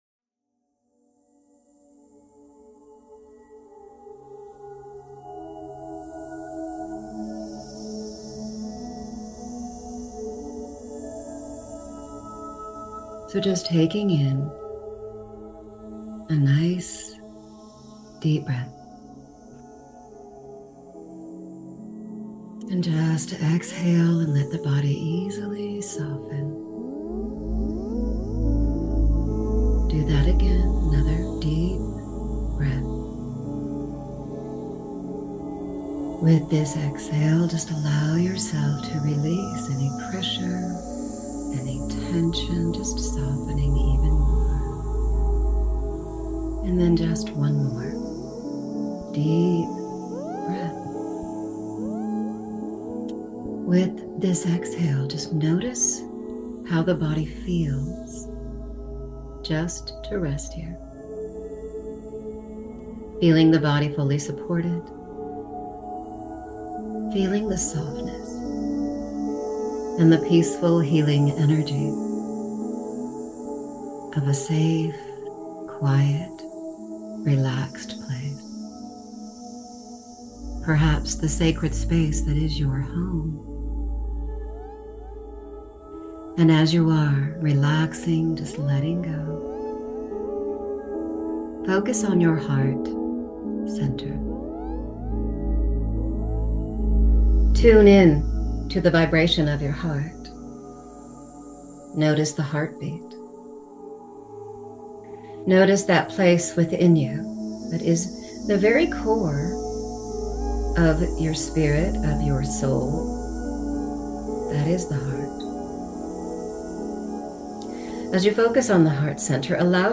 Deep Guided Meditation